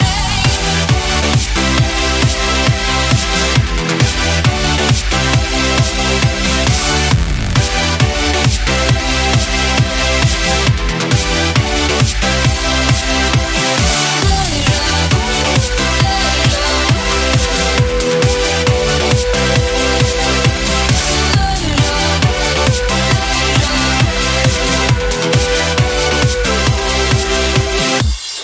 Genere: dance, club, edm, remix